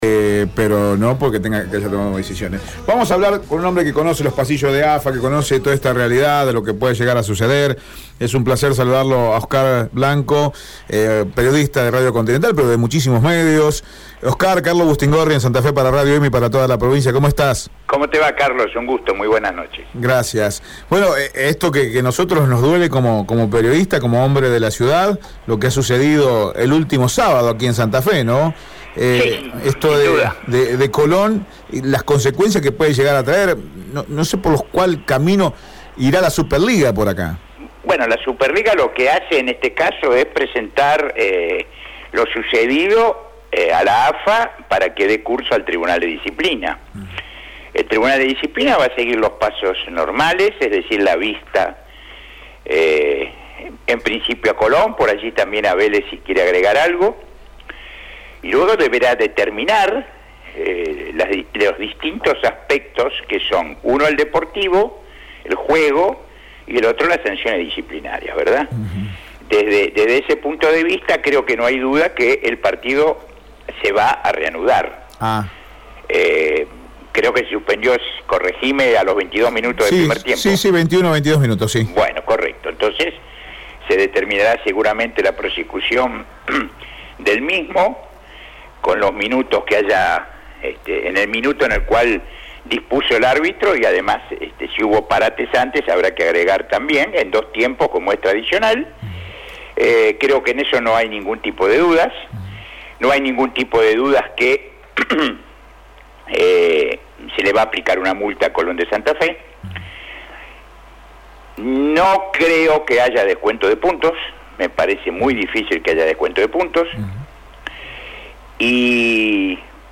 Un reconocido abogado deportivo opinó sobre lo sucedido en Colón